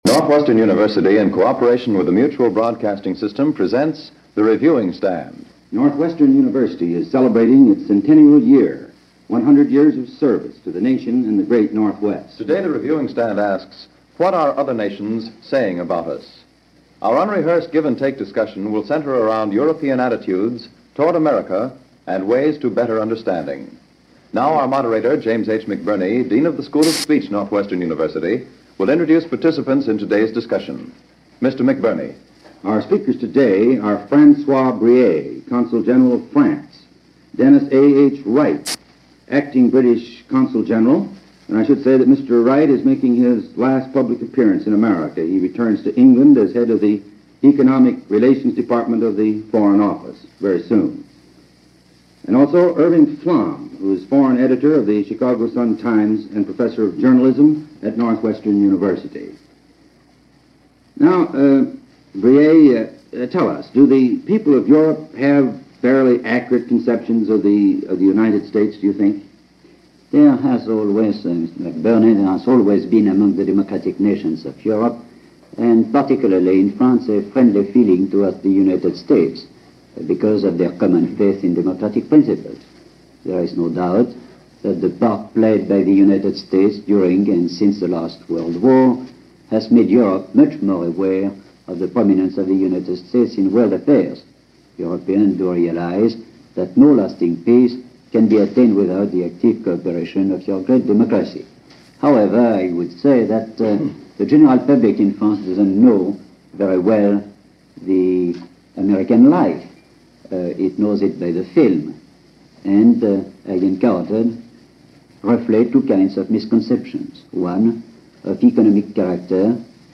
In this panel discussion for the radio program Northwestern University Reviewing Stand, the general opinion of Americans was based on what was seen in Popular Culture; movies, magazines and books – it was more based on a cultural perception that was,